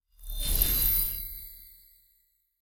Free Frost Mage - SFX
ice_bolt_01.wav